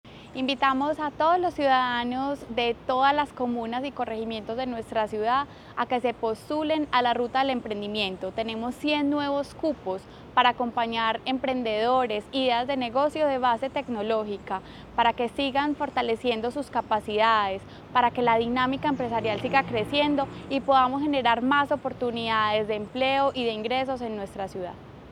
Palabras de María Fernanda Galeano Rojo, secretaria de Desarrollo Económico